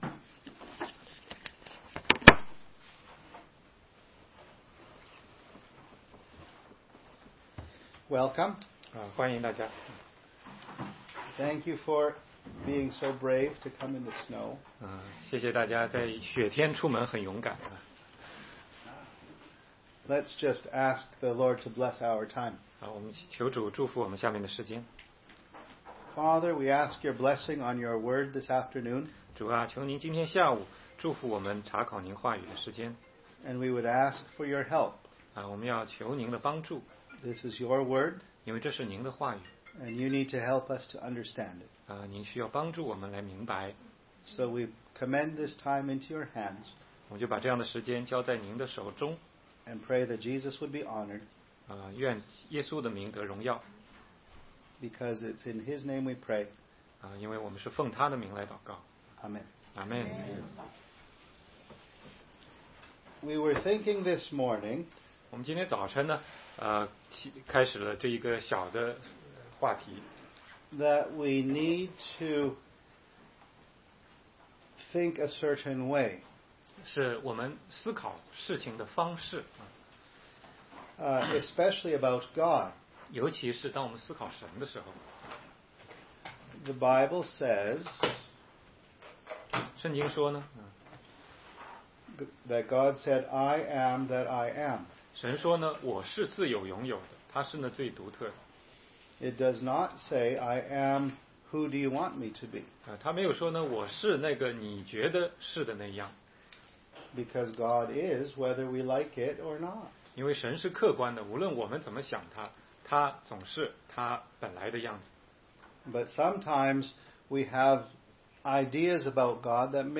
16街讲道录音 - 耶稣来到世上是为了什么
答疑课程